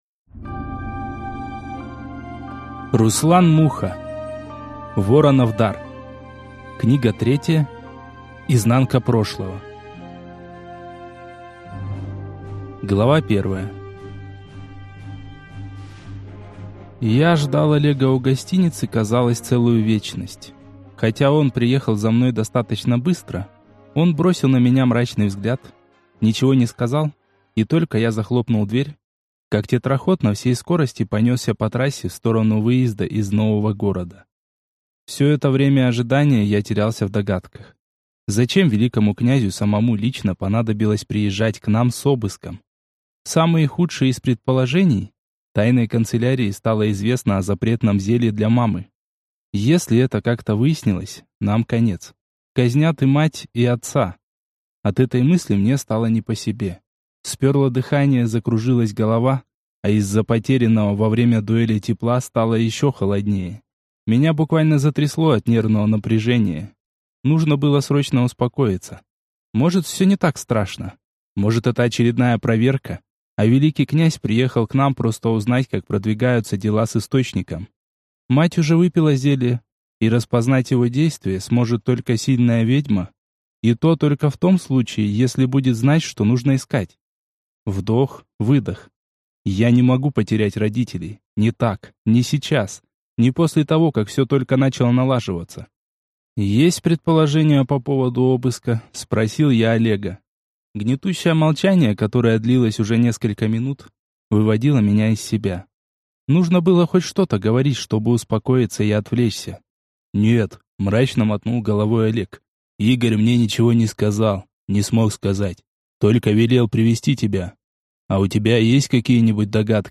Аудиокнига Воронов дар. Книга 3. Изнанка прошлого | Библиотека аудиокниг
Прослушать и бесплатно скачать фрагмент аудиокниги